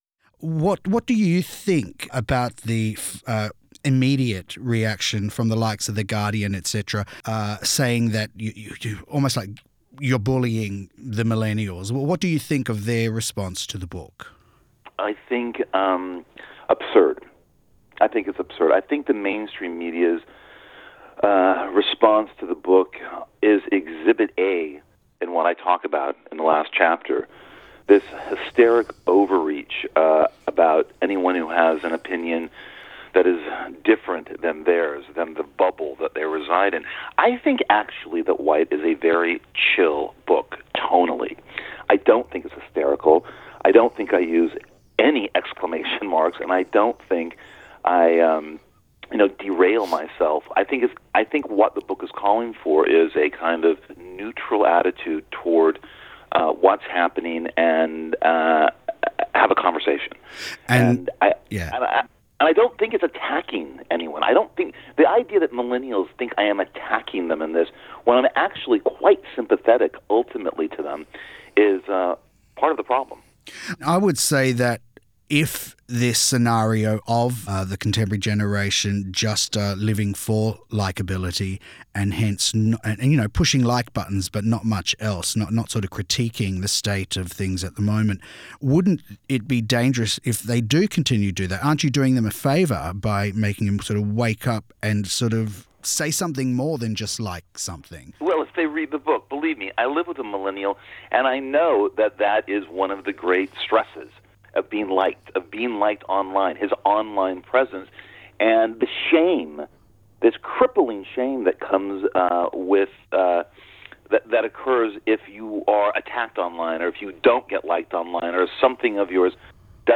Interview
Above: audio of Bret Easton Ellis discussing helicopter parenting and the perils of social media.